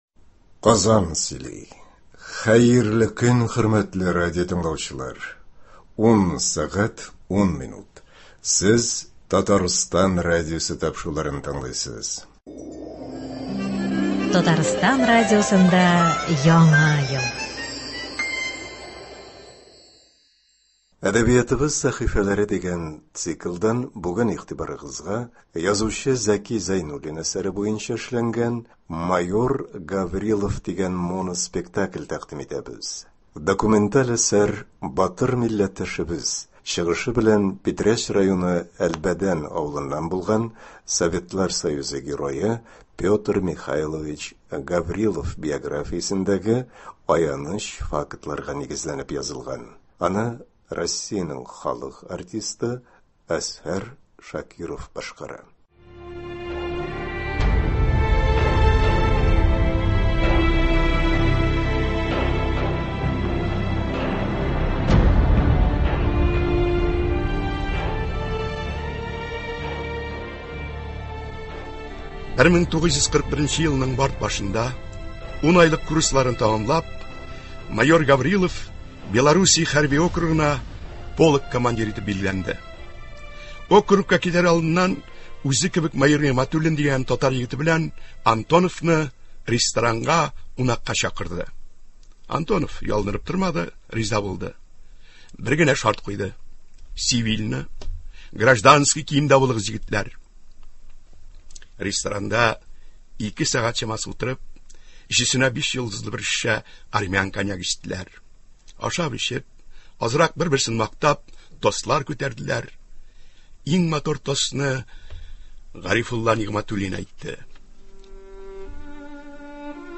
Документаль әсәр батыр милләттәшебез, чыгышы белән Питрәч районы Әлбәдән авылыннан булган Советлар Союзы Герое Петр Михайлович Гаврилов биографиясендәге аяныч фактларга нигезләнеп язылган. Аны Россиянең халык артисты Әзһәр Шакиров башкара.